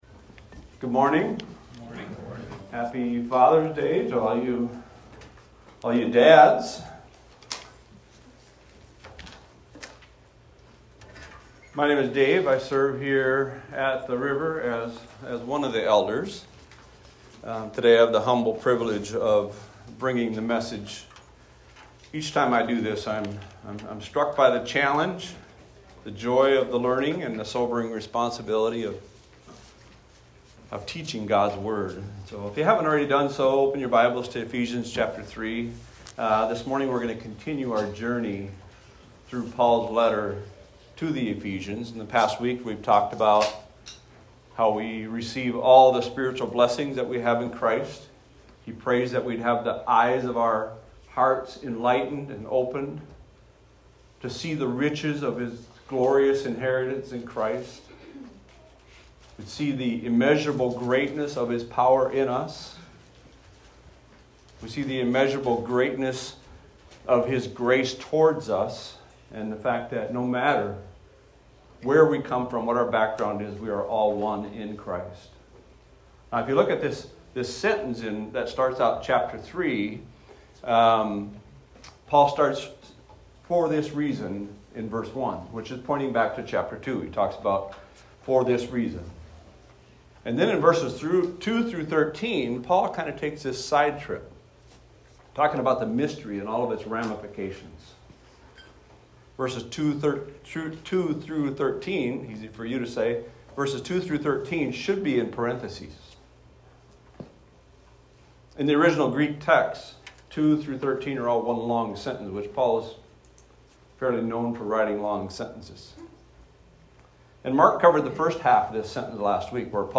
This is a recording of a sermon titled, "The Gift of Grace."